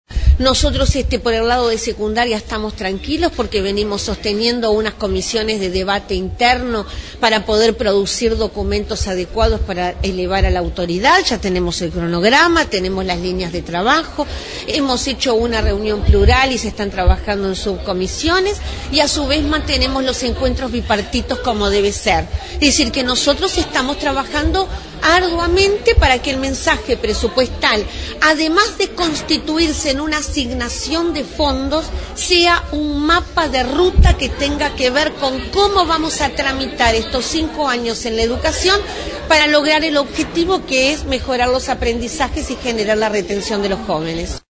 Puente explicó en rueda de prensa que trabajan para que "el mensaje presupuestal, además de constituirse en una asignación de fondos sea un mapa de ruta que tenga que ver sobre cómo vamos a tramitar estos cinco años en la educación".